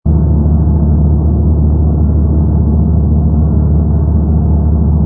rumble_h_fighter.wav